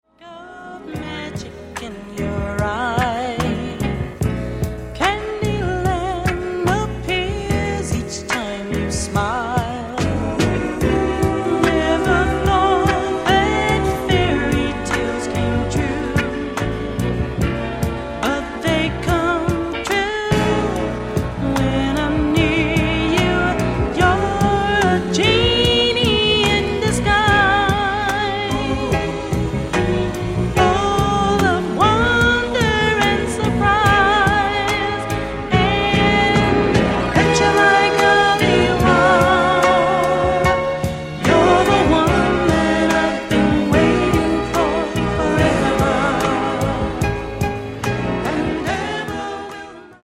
VOLUME :: 69 :: - LOVE BALLADS -